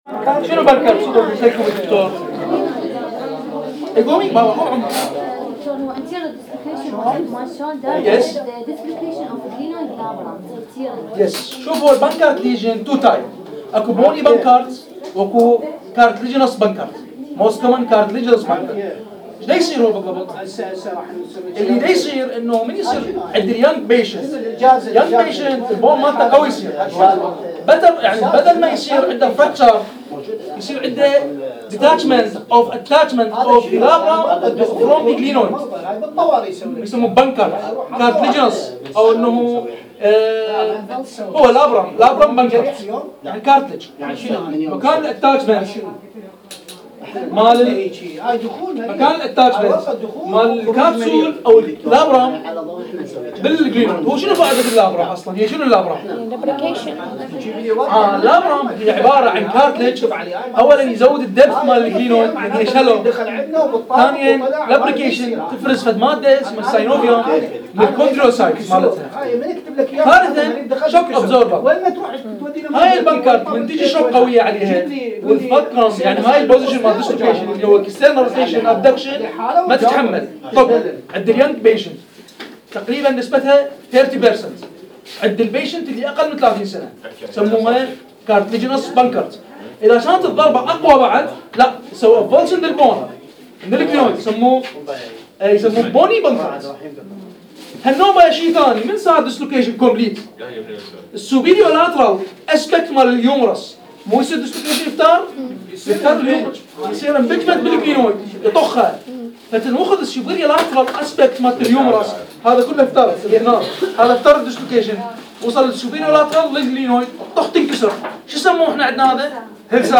upper limb fractures-a clinical session for group E